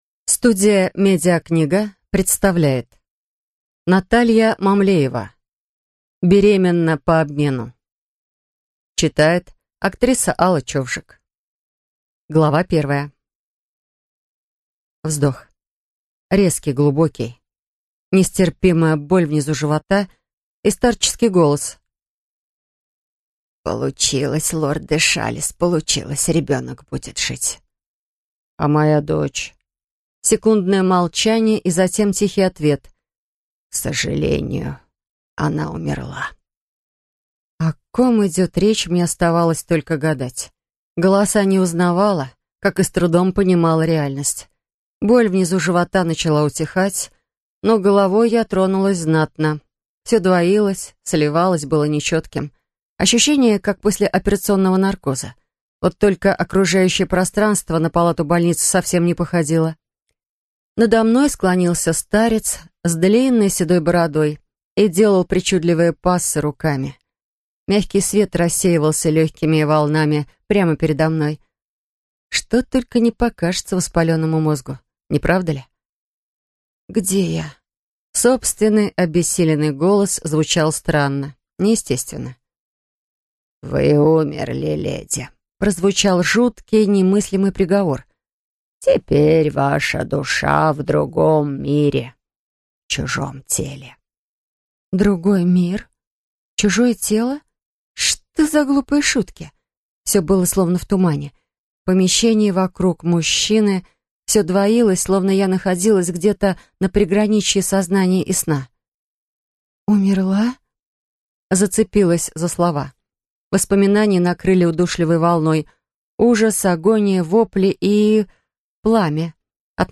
Аудиокнига Беременна по обмену. Часть 1 | Библиотека аудиокниг